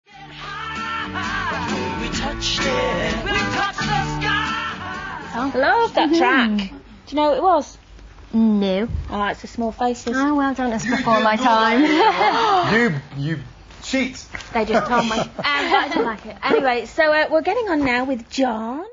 Keavy appeared as a guest presenter on ITV1`s show This Morning.
All crackling/rustling is from the microphones rubbing on clothing.